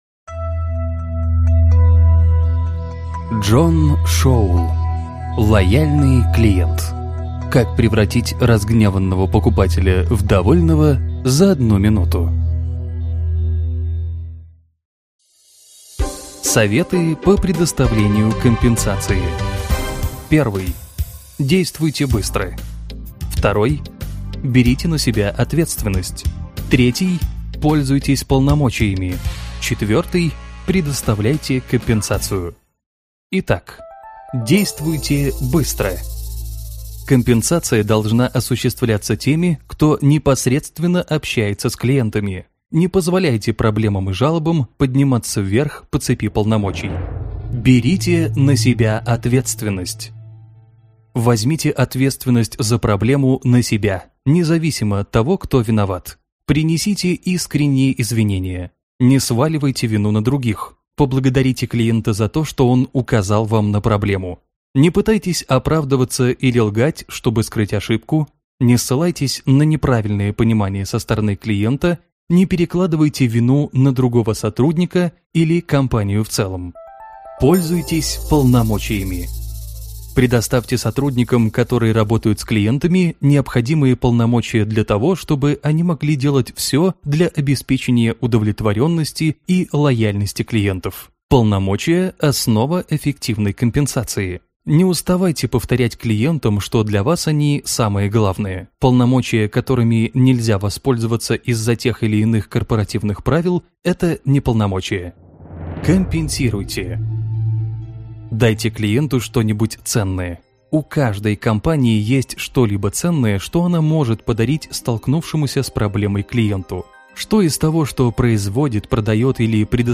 Аудиокнига Лояльный клиент: Как превратить разгневанного покупателя в довольного за 1 минуту | Библиотека аудиокниг